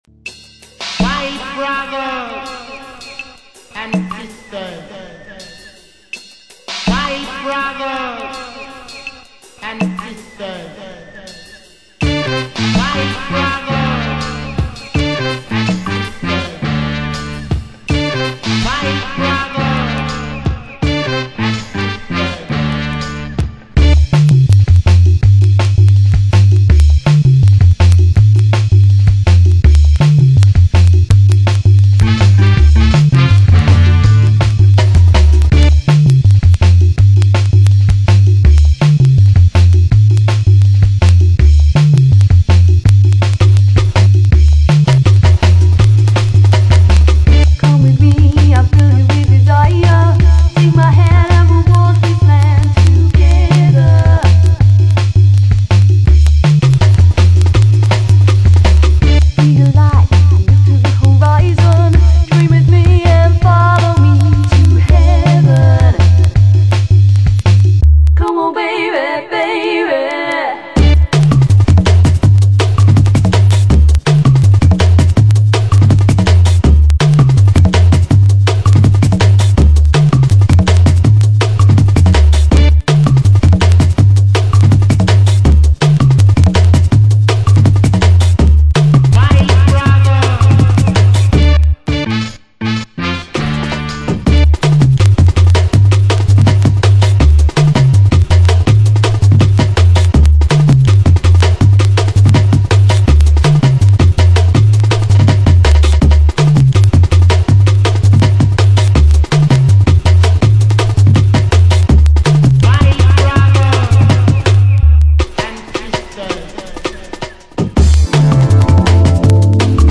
Саундтрек из фильма
Drum